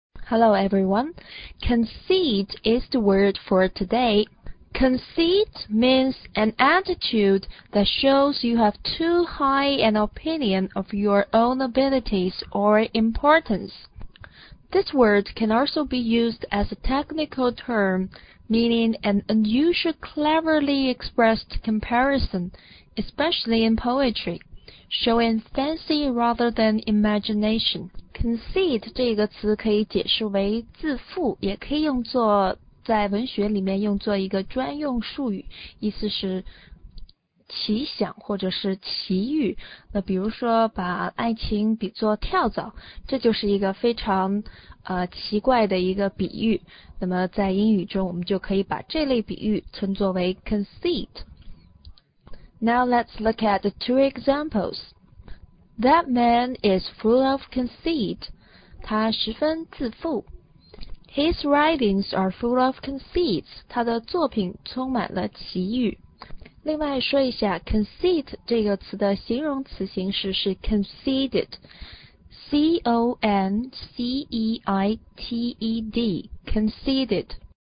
conceit重音在第二个音节ceit上，而且这里的e发长音[i:]，字母组合ceive以及ceit、ceipt中e发长音[i:]的还有：deceive, conceive, perceive, receive, deceit, receipt.